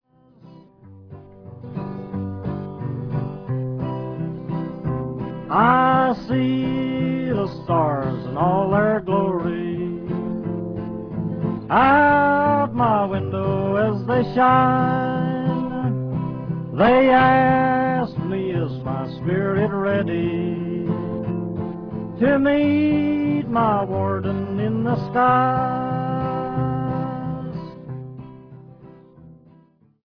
guitar
Recorded in New York between 1944 and 1949.